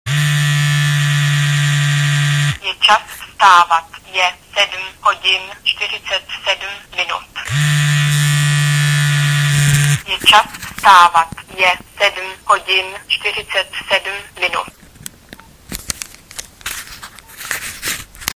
Mluvící hodiny v telefonech Nokia
spustí nejprve vibrací a následnou hláškou i při vypnutém telefonu.